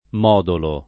[ m 0 dolo ]